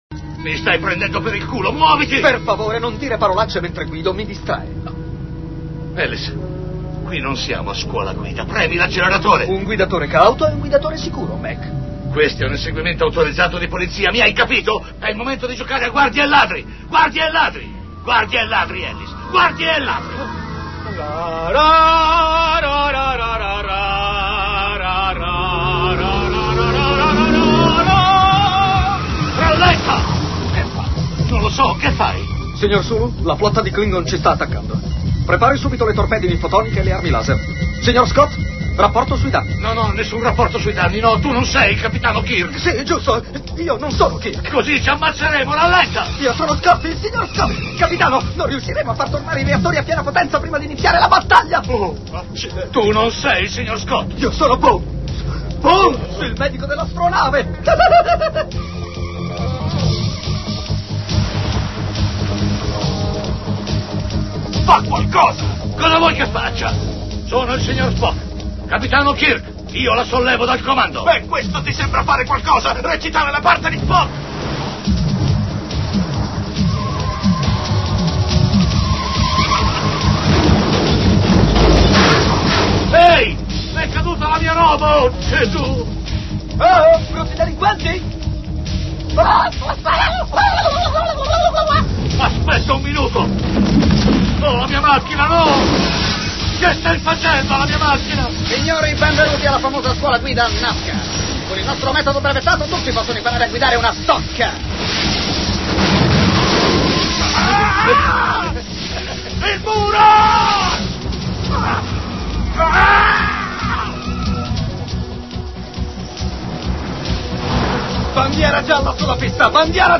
Durante un inseguimento d'auto, l'alienato poliziotto Ellis Fielding interpretato da Dan Aykroyd, si convince di essere alla guida dell'Enterprise e interpreta vari personaggi di Star Trek